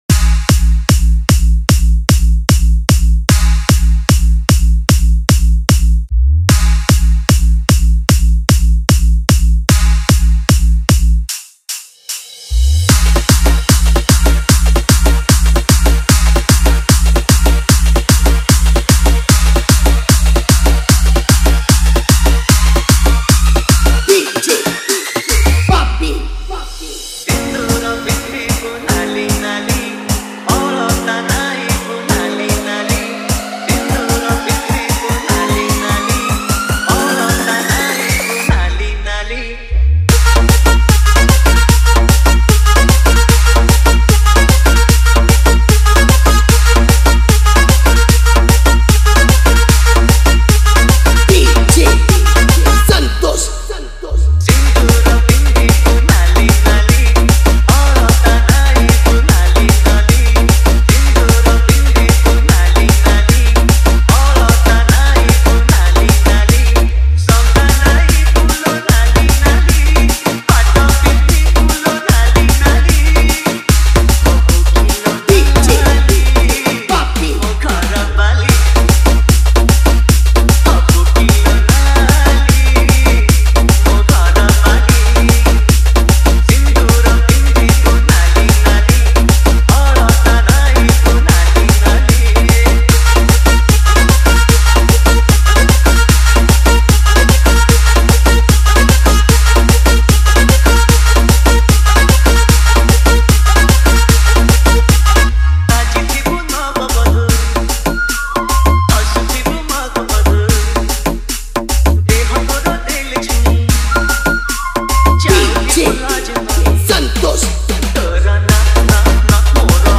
Category:  Odia Old Dj Song